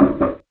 Destroy - OvOPerc.wav